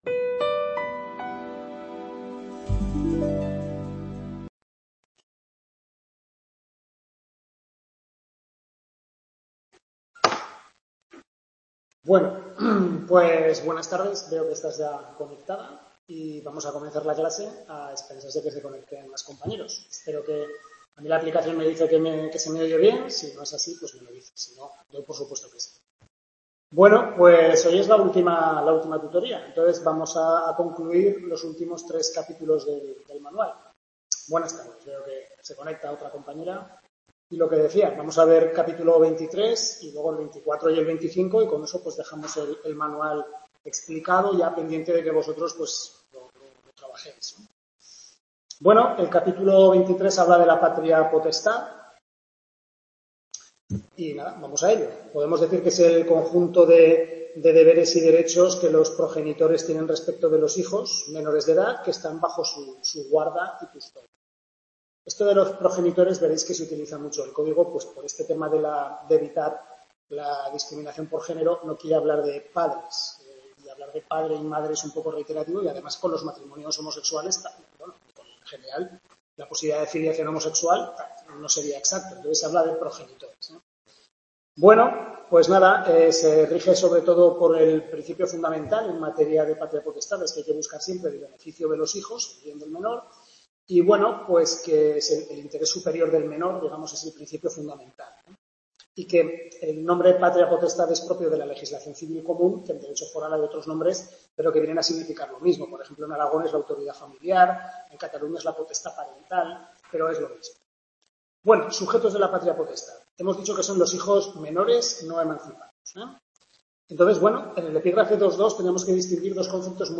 Tutoría 6/6 de Civil I, segundo cuatrimestre (Familia), centro de Calatayud, capítulos 23, 24 y 25 del Manual del profesor Lasarte